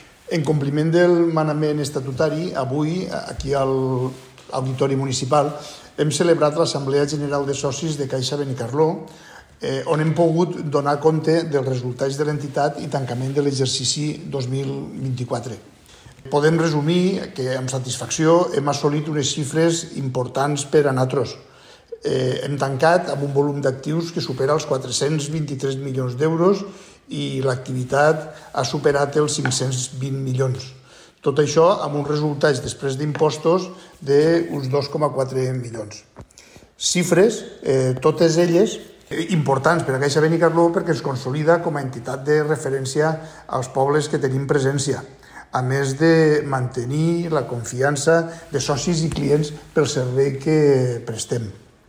Caixa Benicarló donava a conèixer el seu informe anual este dimecres 4 de juny en Assemblea General Ordinària, celebrada a l’Auditori Pedro Mercader de Benicarló, davant les seues sòcies i socis.